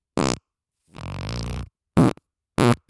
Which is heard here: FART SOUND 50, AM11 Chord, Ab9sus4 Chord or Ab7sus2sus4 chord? FART SOUND 50